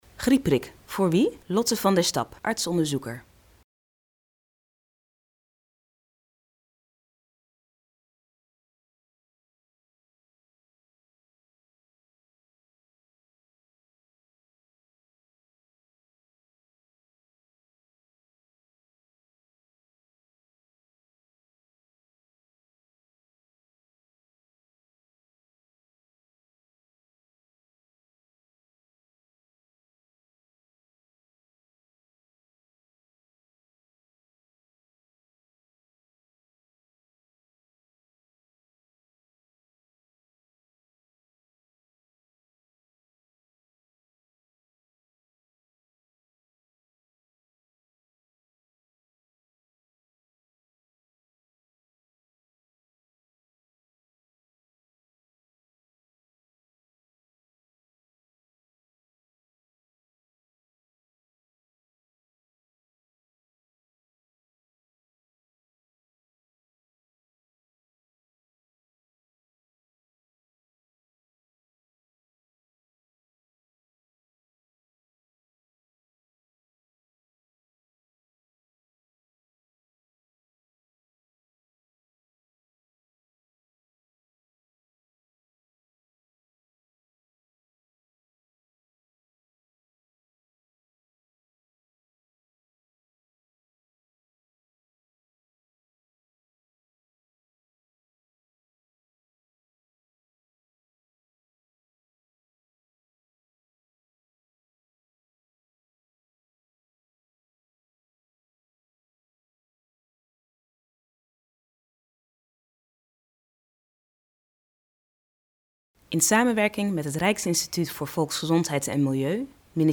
In deze video wordt u door een huisarts uitgelegd voor wie de griepprik bedoeld is